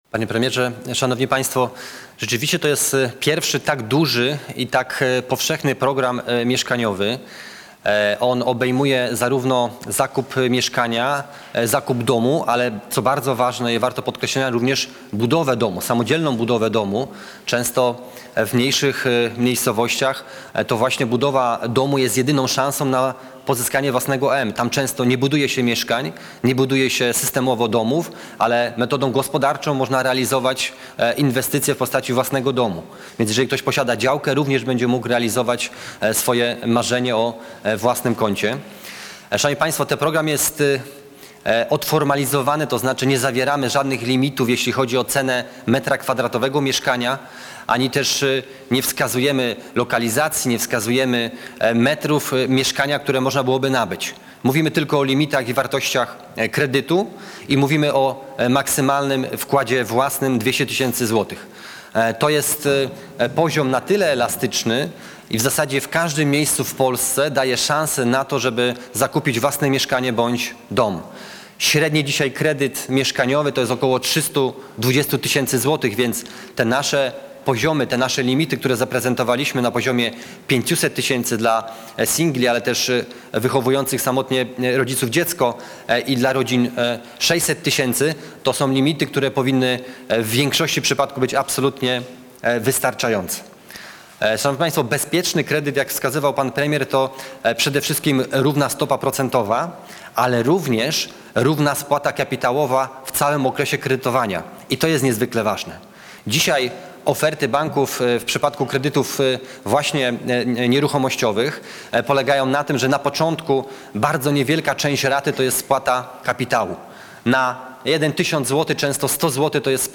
Zapis audio z konferencji